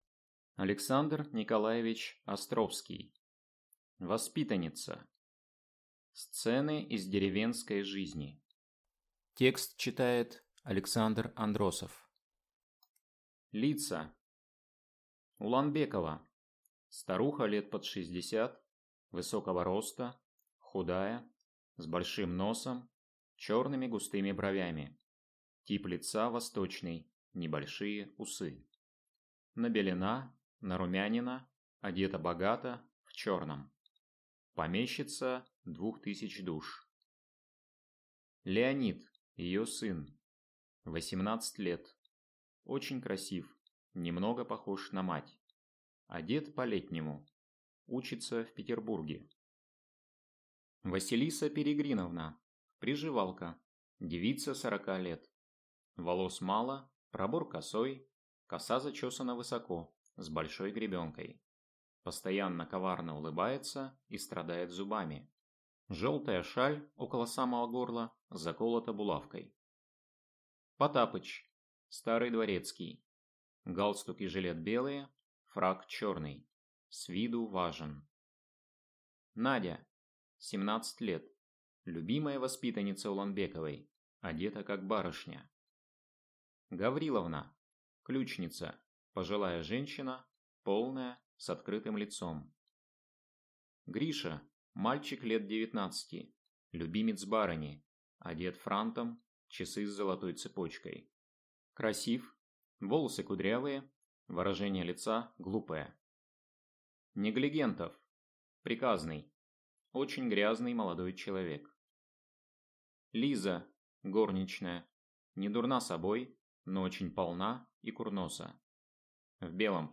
Аудиокнига Воспитанница | Библиотека аудиокниг